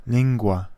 Ääntäminen
US : IPA : [ˈspiːt͡ʃ]